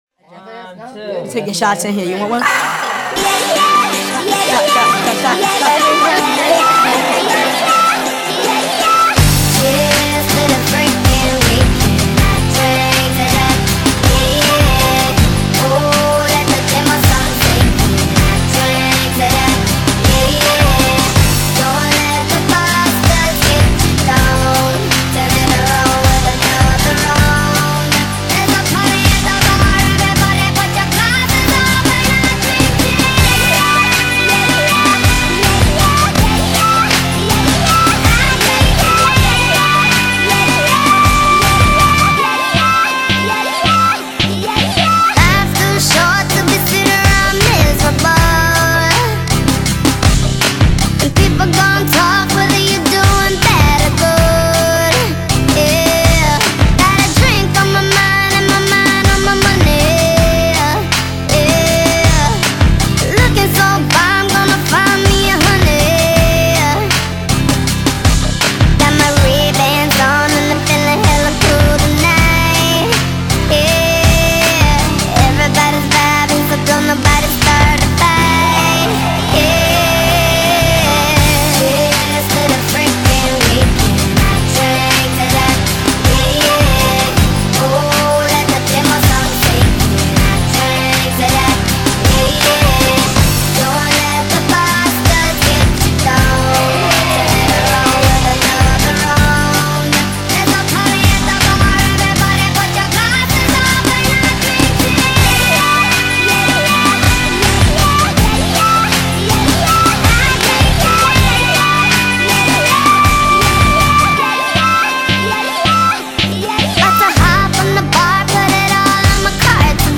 and audio in G